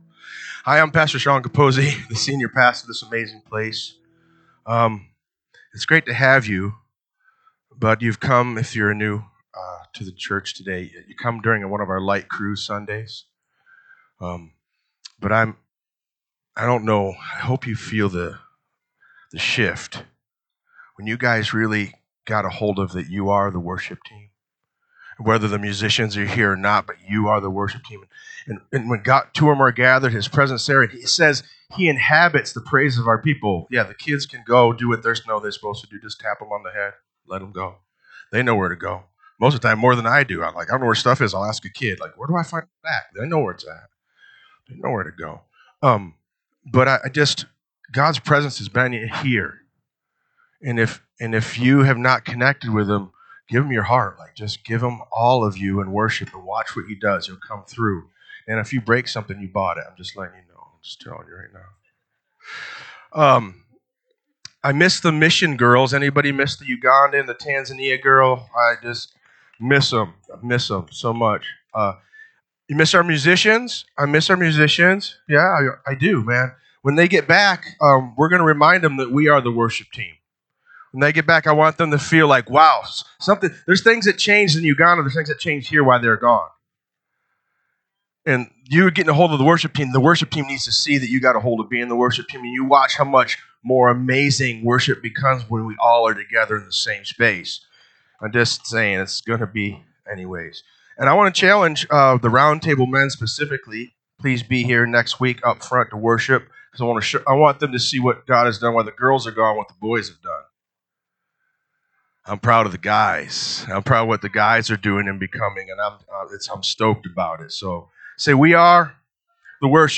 NSCF Sermons Online Acts of the Apostles Episode 2 Jul 20 2025 | 00:51:05 1x 00:00 / 00:51:05 Subscribe Share RSS Feed Share Link Embed